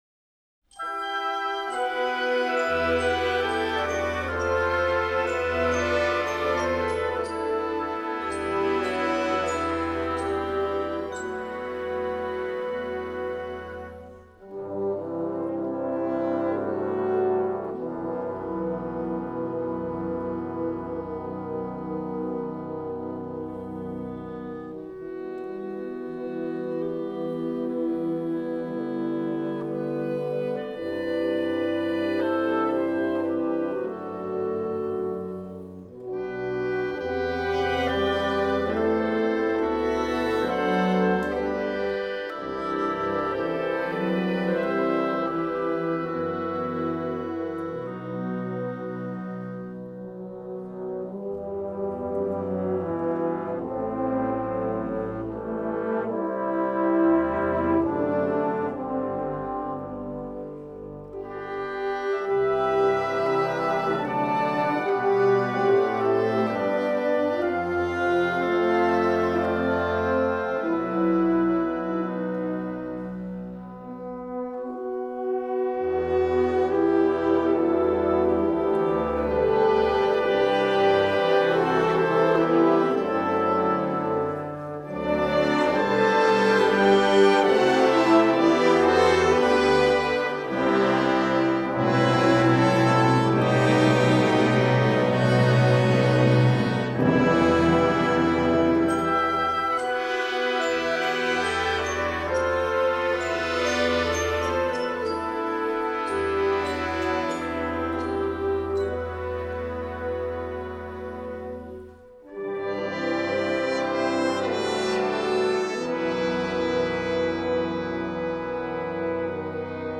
Genre: Wind Orchestra